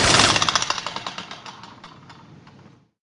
minigun_stop.wav